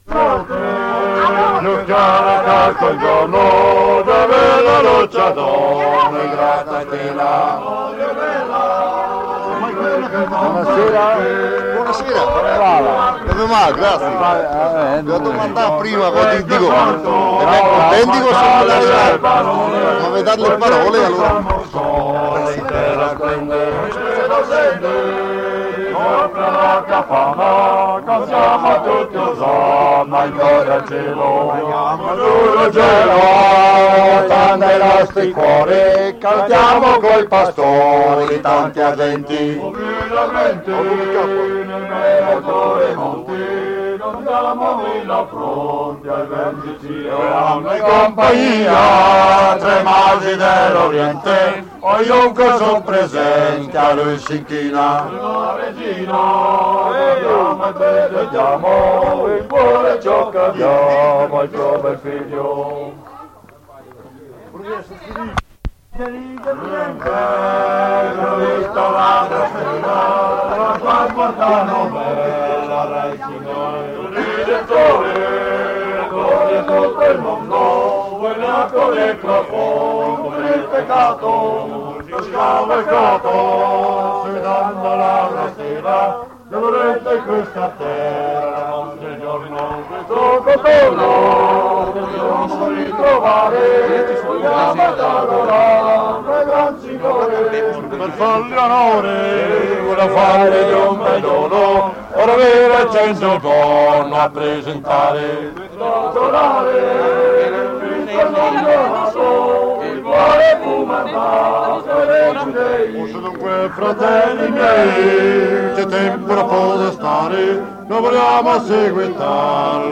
a Palù del Fersina in Val dei Mòcheni